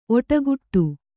♪ oṭaguṭṭu